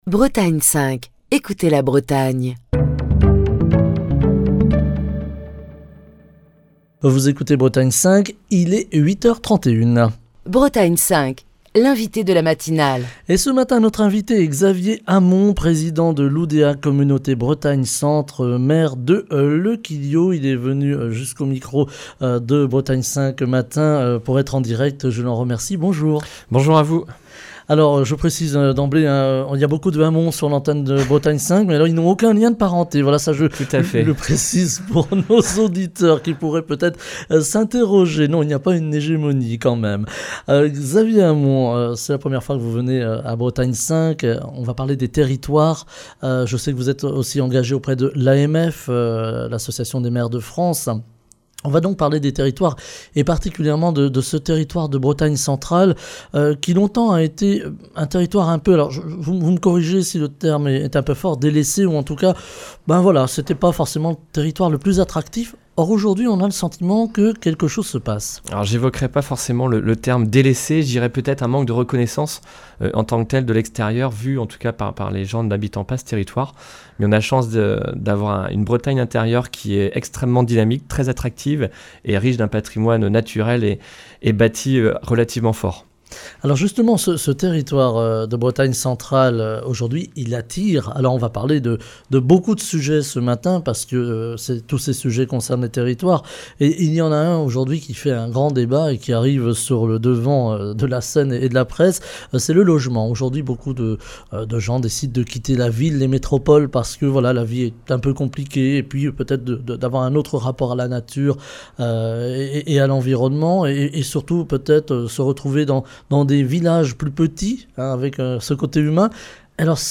Émission du 30 septembre 2022. Le centre Bretagne connait un véritable engouement depuis quelques années, plus encore depuis la crise du Covid.